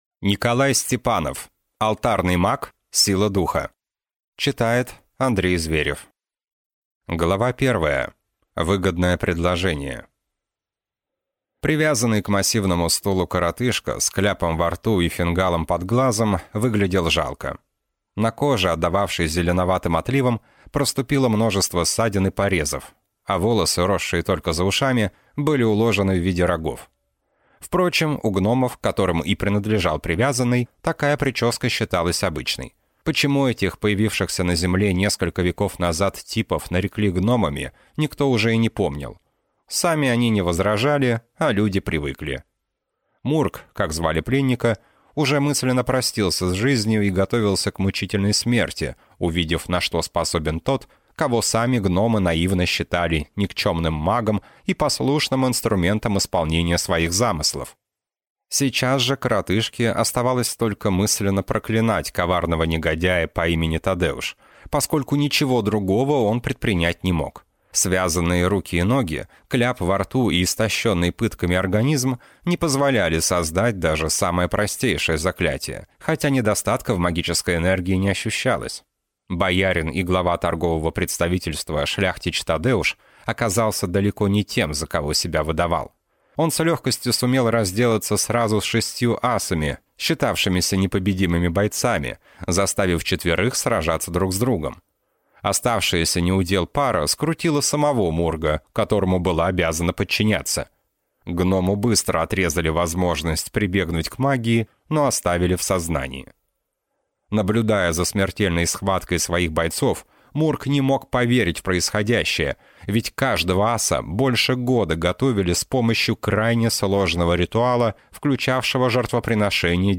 Аудиокнига Алтарный маг. Сила духа | Библиотека аудиокниг